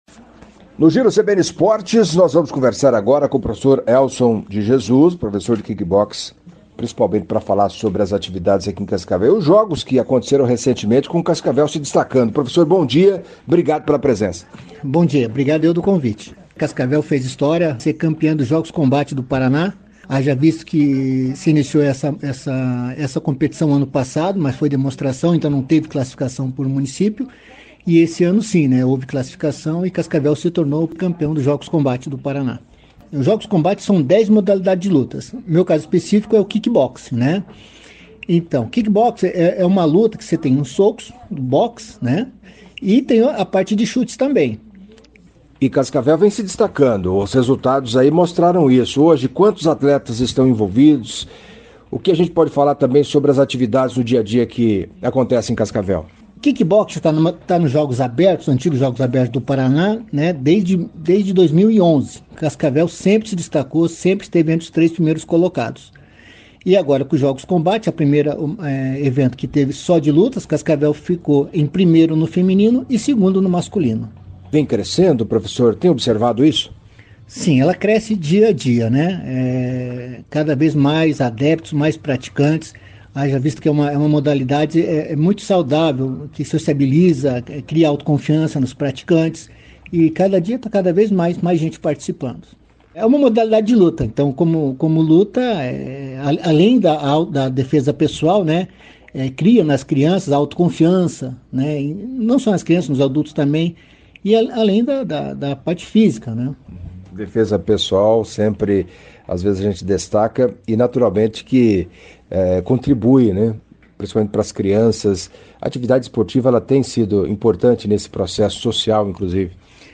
Em entrevista ao Giro CBN Esportes o professor de Kickboxing